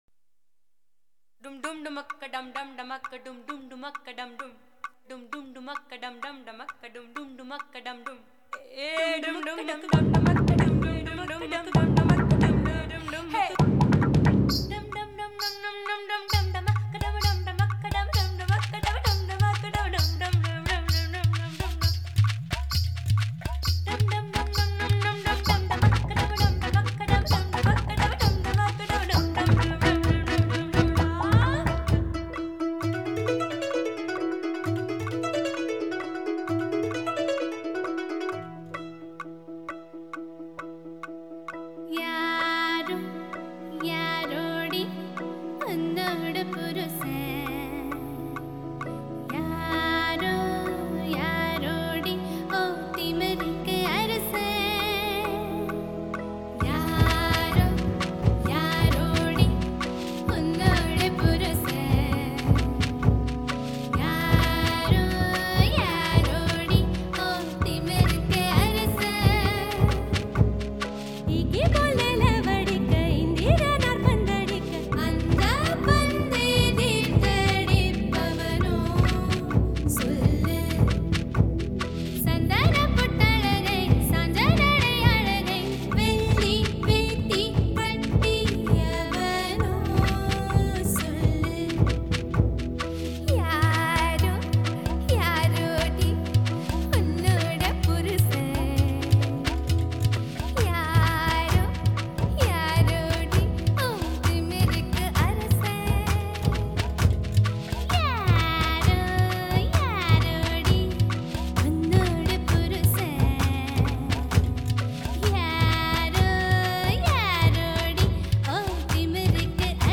Genre: Soundtracks & Musicals.